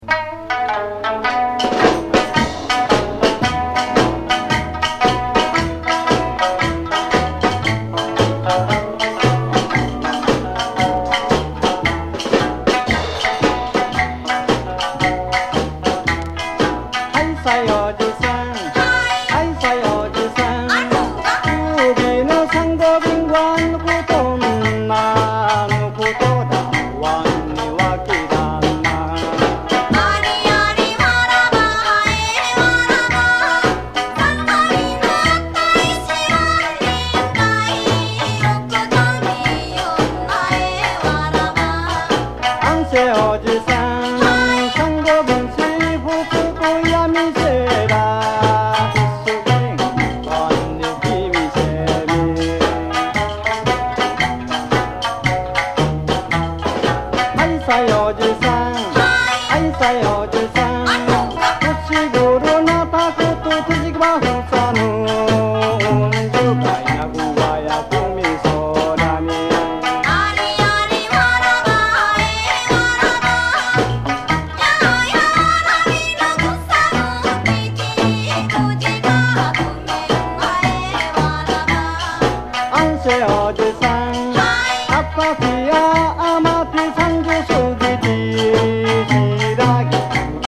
沖縄伝統音楽にロックやソウルのグルーヴを施した人気コンピレーション